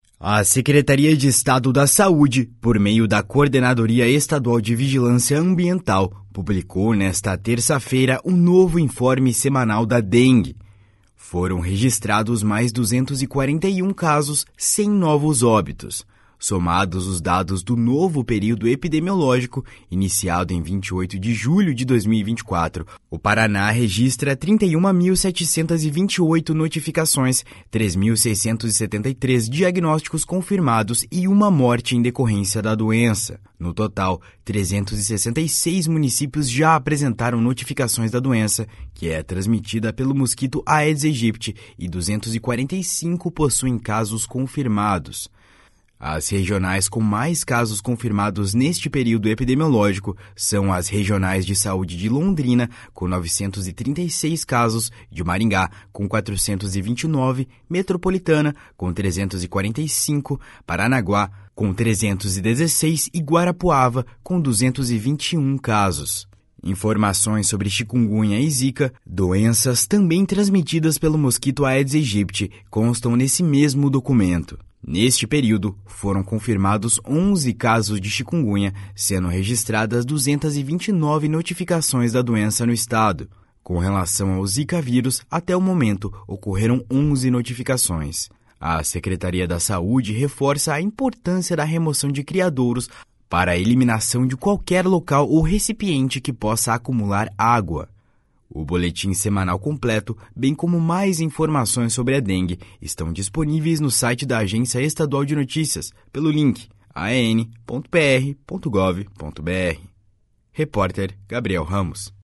BOLETIM SEMANAL DA DENGUE.mp3